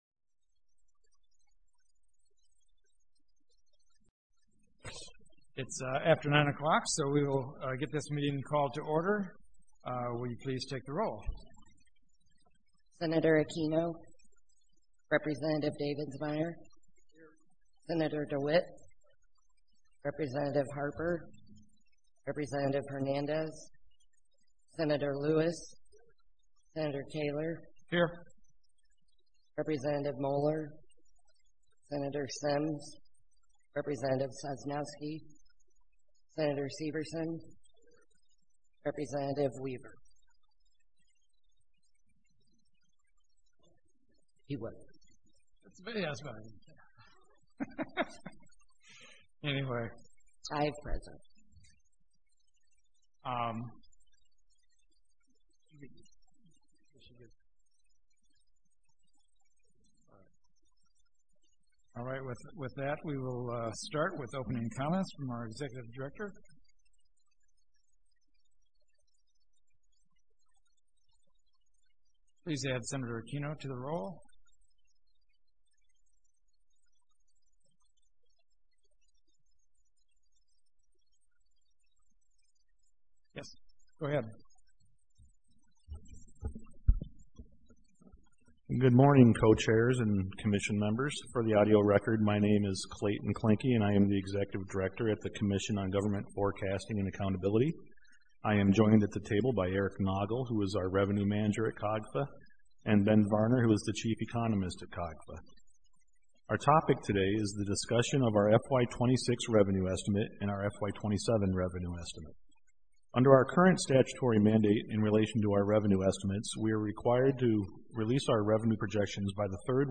Room 400, Capitol Building, Springfield, IL